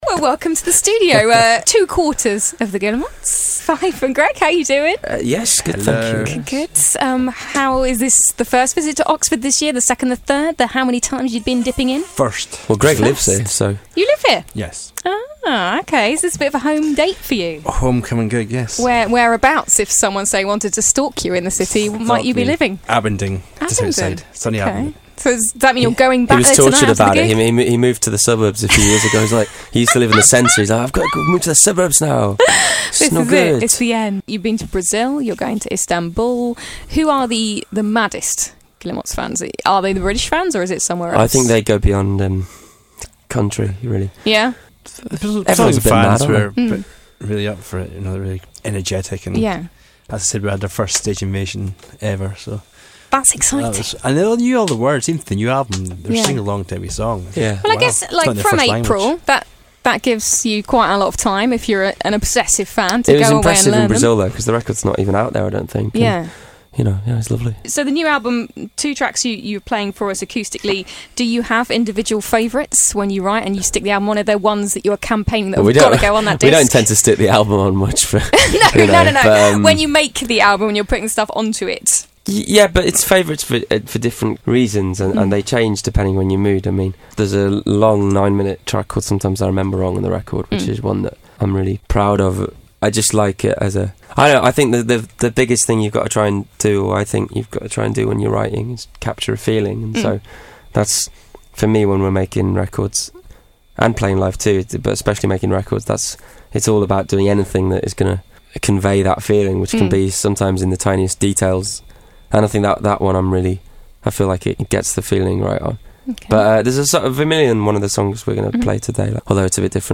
Glide's Morning Glory Interview with Guillemots Part 1